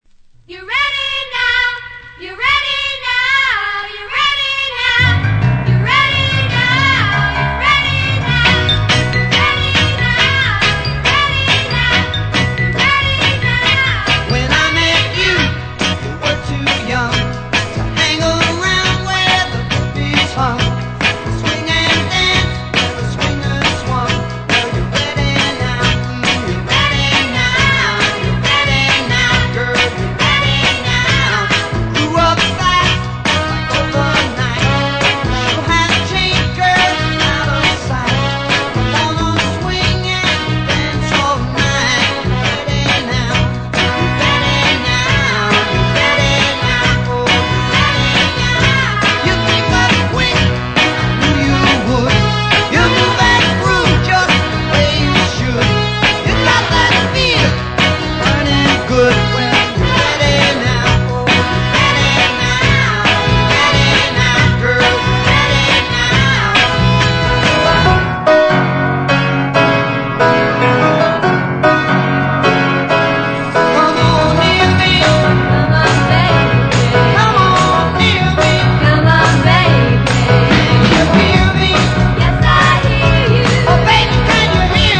Genre: SOUL ORIG / REISS